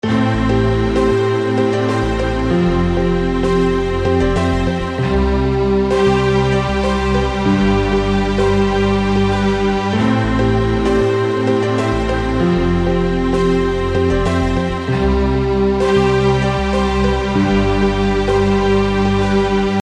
少了一个Grooveloop没有节拍
标签： 97 bpm Hip Hop Loops Groove Loops 3.33 MB wav Key : Unknown
声道立体声